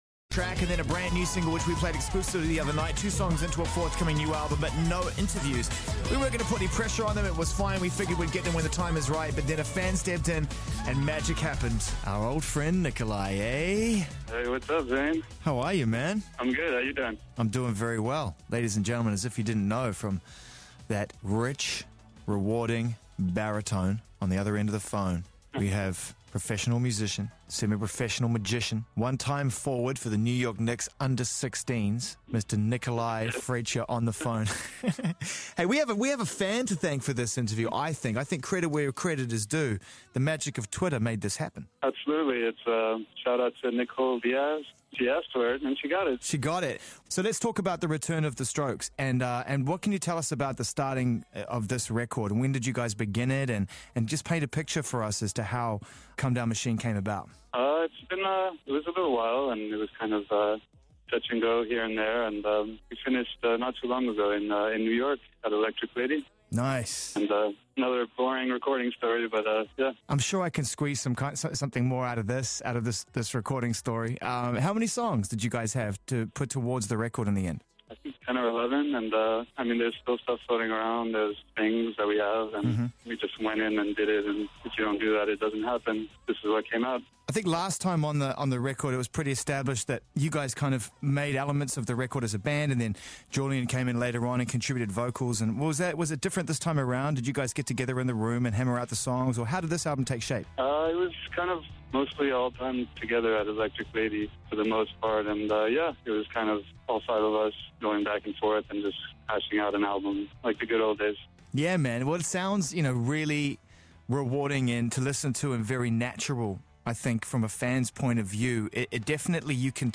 Depois de conversa no twitter, Niko e Zane conseguiram combinar uma entrevista leve que foi ao ar hoje na BBC Radio 1.
No momento em que Zane perguntou se haviam planos de uma turnê, Niko pausou por um segundo para responder que “não sabe” e solta um risinho, Zane soltou uma gargalhada de volta e mudou a pergunta, perguntando se pessoalmente ele gostaria de sair em turnê, a resposta de Niko foi que ele “adoraria”.